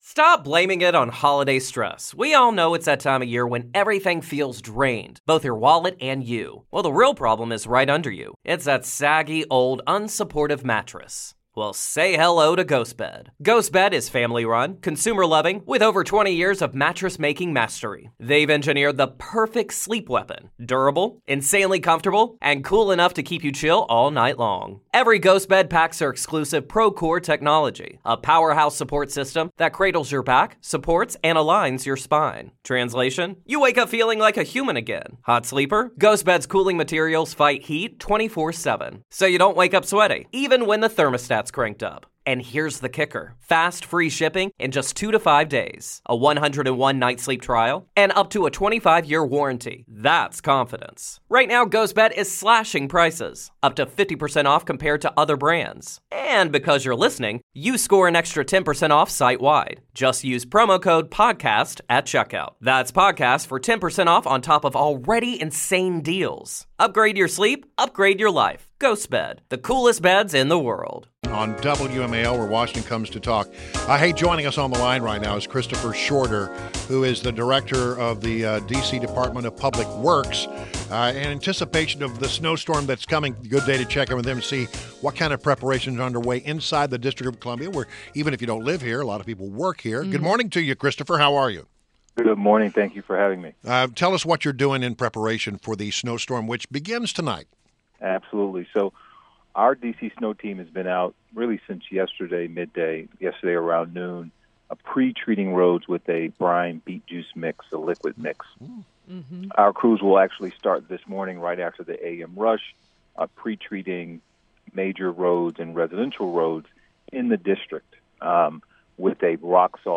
Interview – CHRISTOPHER SHORTER – DC Director of the Department of Public Works – previewed how DC is preparing the city in anticipation of the snow.